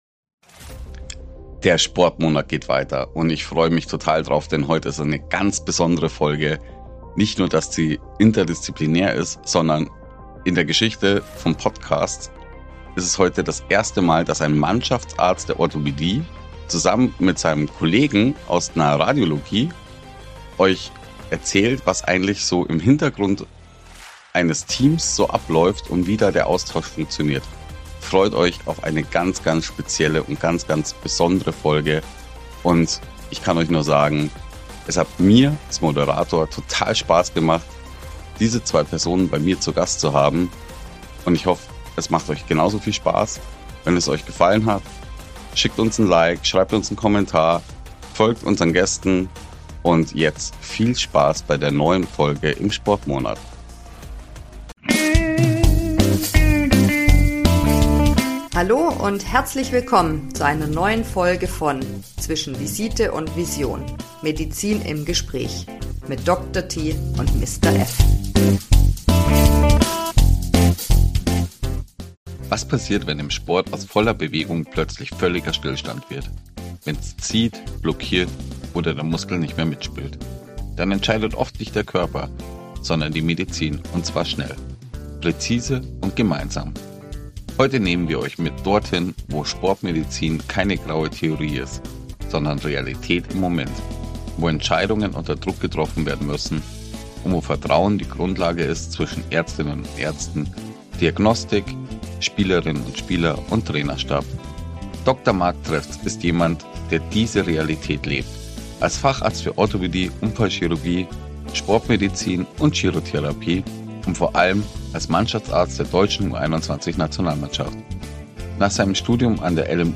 Ein Gespräch über Vertrauen, Tempo und Teamgeist – über Medizin, die Leistung möglich macht, und Menschlichkeit, die sie begleitet.